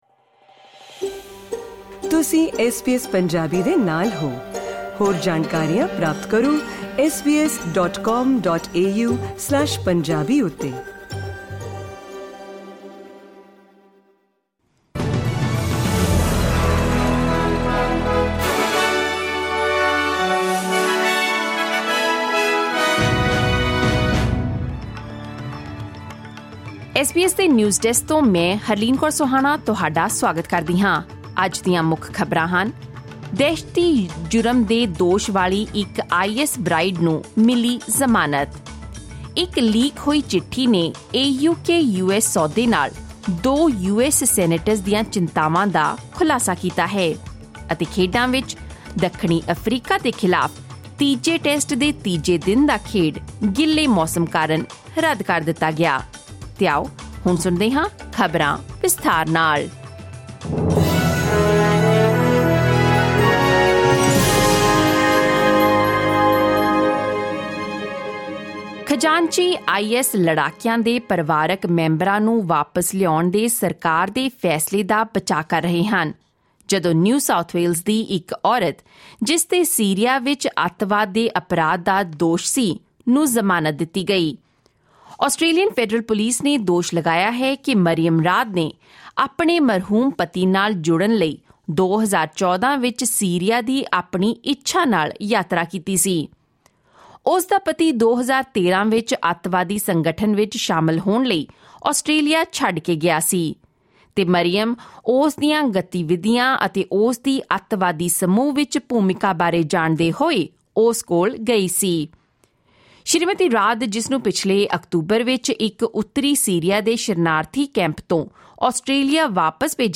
SBS Punjabi Australia News: Friday 6 January 2023